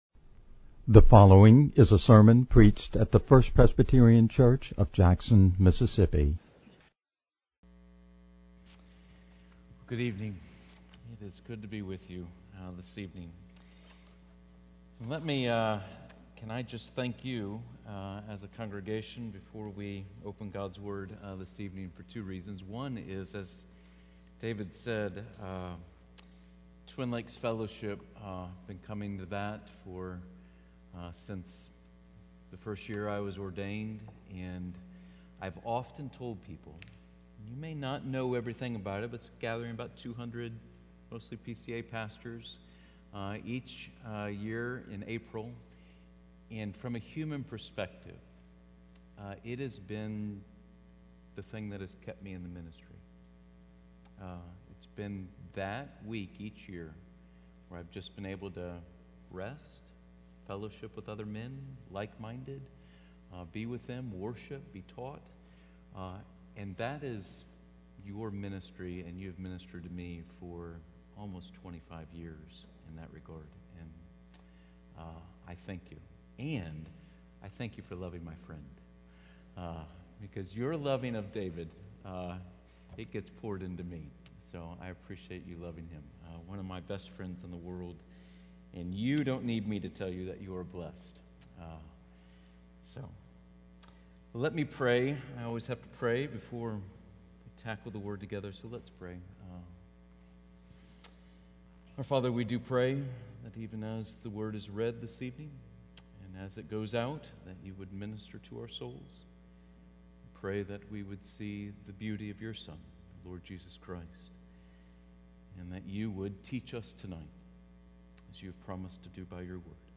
JRM-Lecture-1.mp3